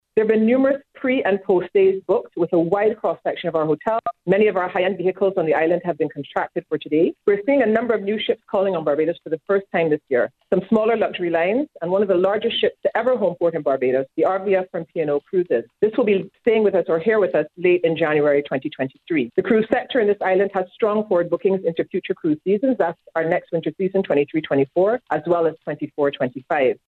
during a media briefing this morning